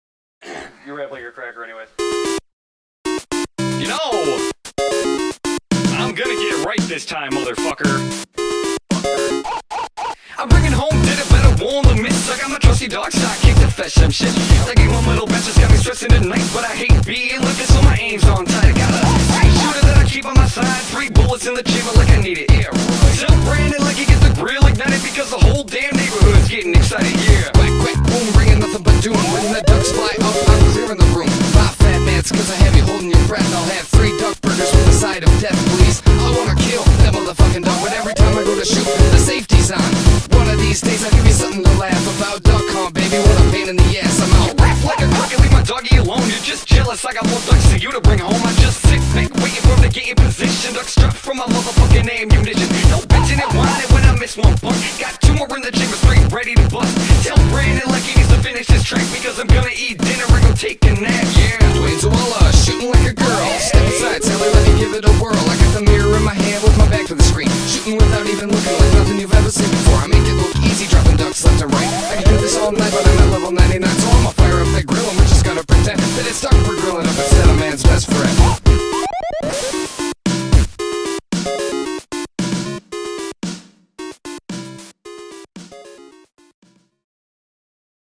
BPM113
Audio QualityCut From Video